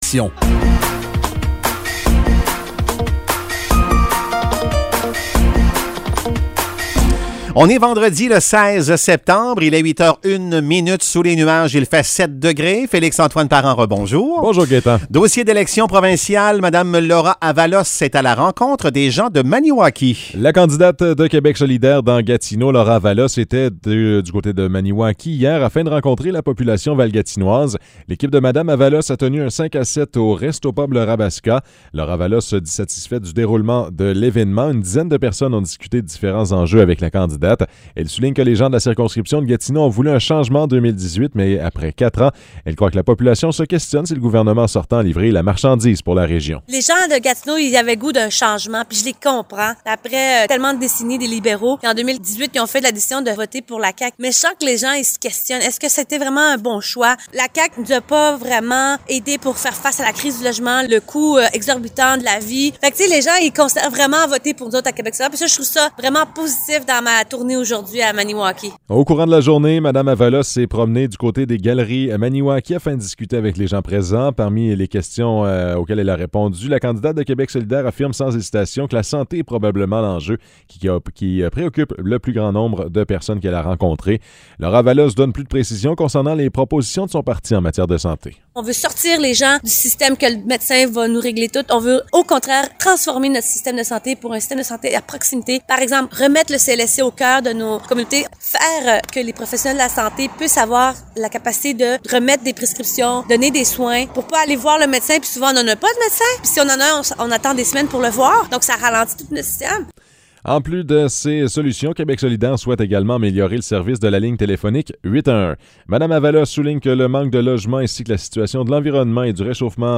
Nouvelles locales - 16 septembre 2022 - 8 h